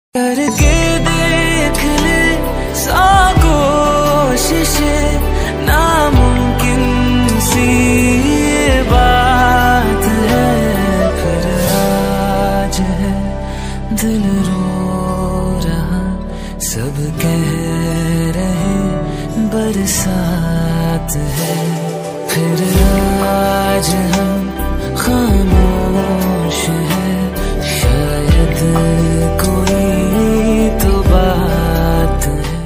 Sad Song Ringtone